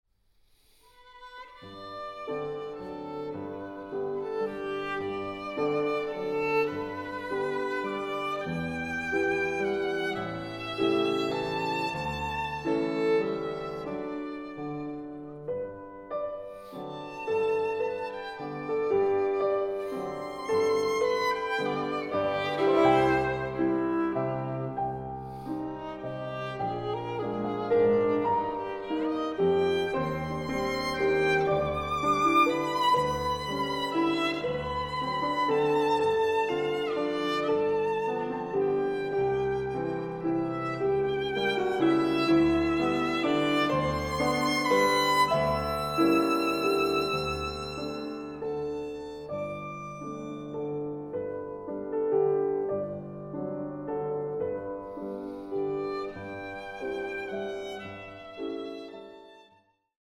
Sonaten für Klavier und Violine
Klavier
Violine